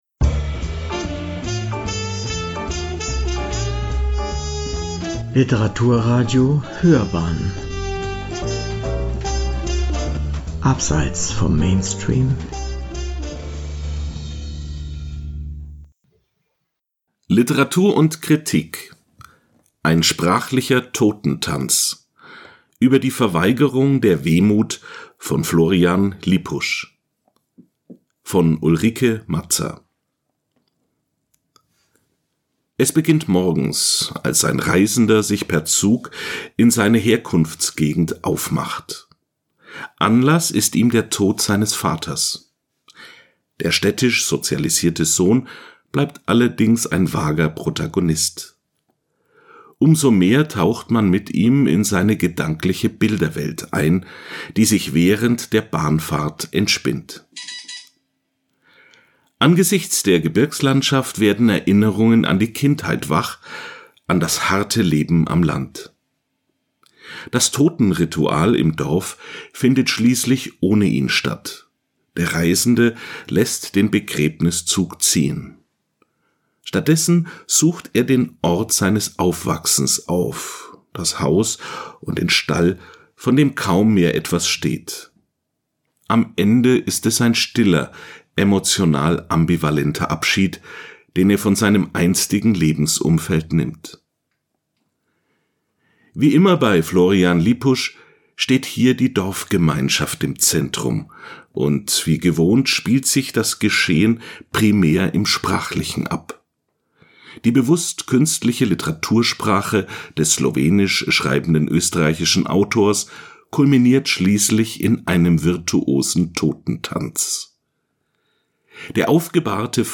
Wir dürfen Beiträge aus den verschiedenen Ausgaben von Literatur und Kritik für unser Literaturpodcastradio vertonen.